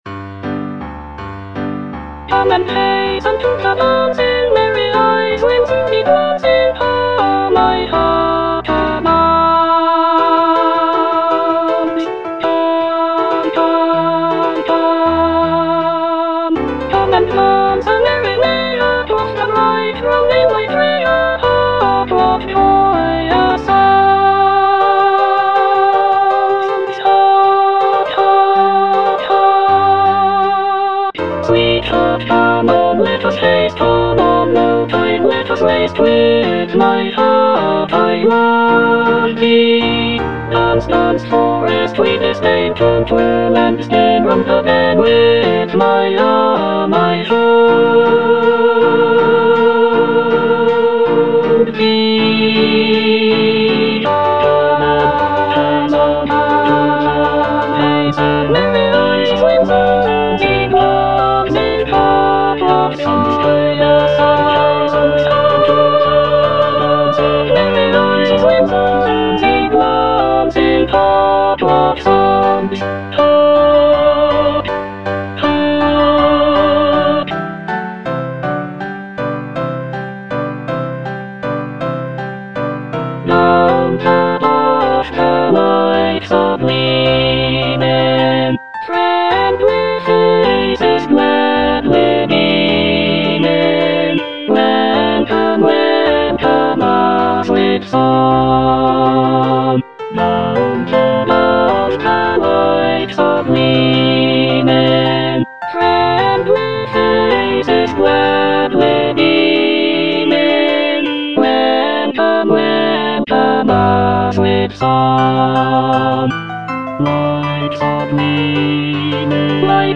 E. ELGAR - FROM THE BAVARIAN HIGHLANDS The dance - Alto (Emphasised voice and other voices) Ads stop: auto-stop